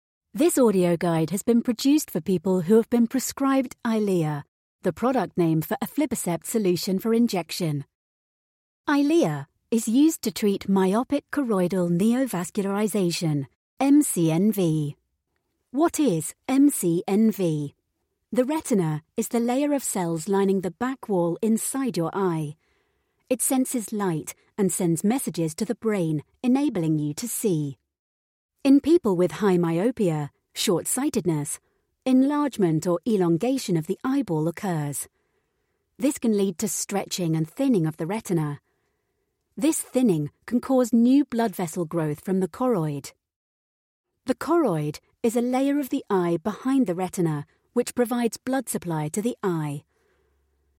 Englisch (Britisch)
Natürlich, Vielseitig, Freundlich, Warm, Corporate